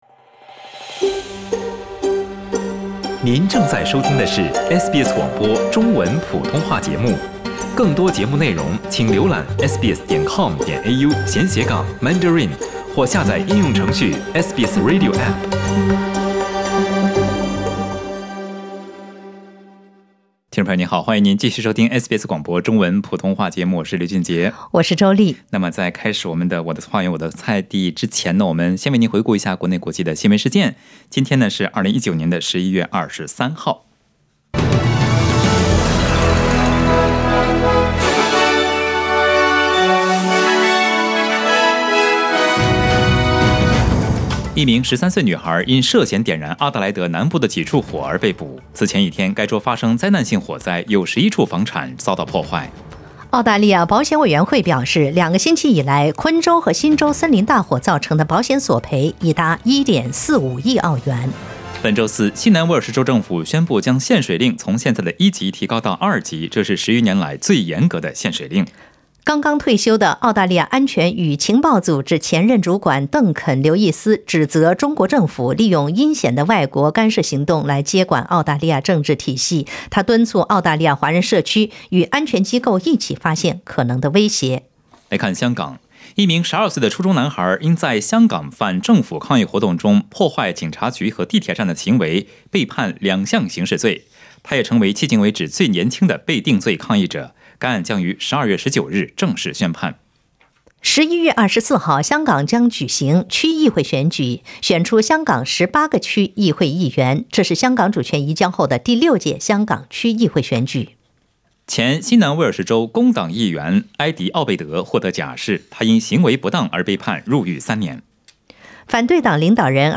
SBS早新闻 （11月23日）